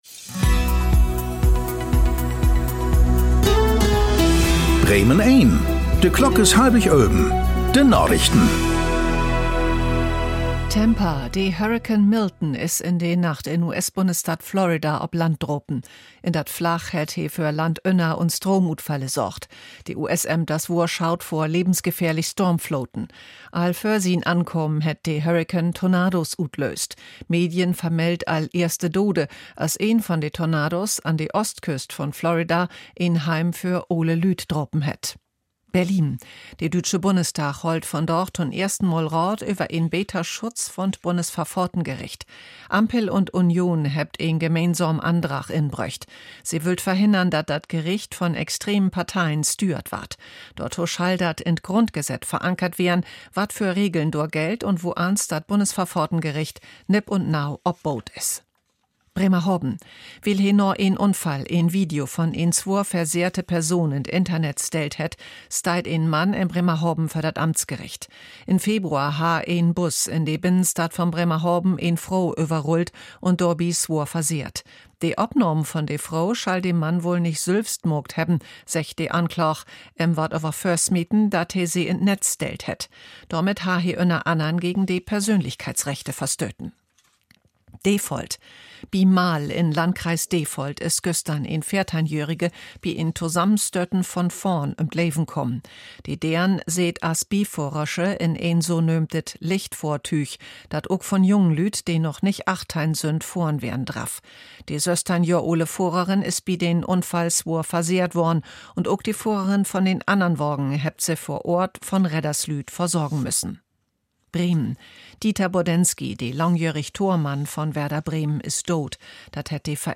Aktuelle plattdeutsche Nachrichten werktags auf Bremen Eins und hier für Sie zum Nachhören.
… continue reading 1114 episodes # Tägliche Nachrichten # Nachrichten # Thu Apr 01 11:24:10 CEST 2021 Radio Bremen # Radio Bremen